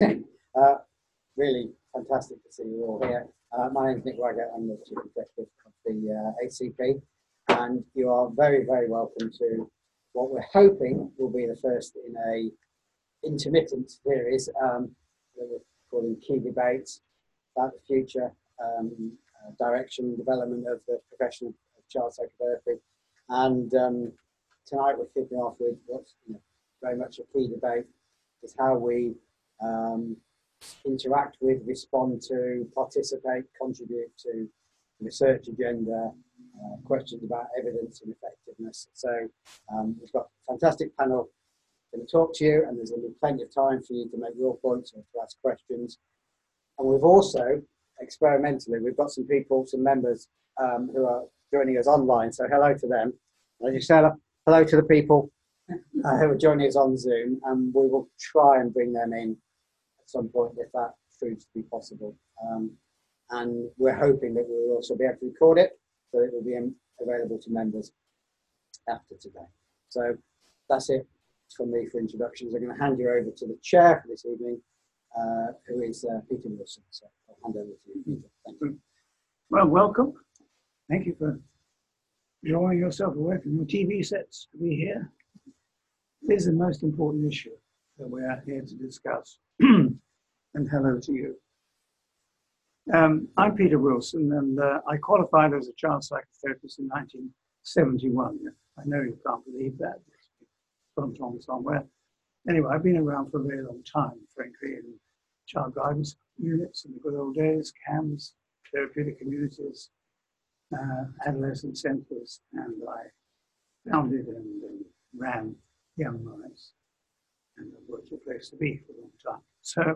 For those who were not able to attend the debate, we are making available the audio recording here: Where next for research into the evidence of effectiveness in psychoanalytic child psychotherapy?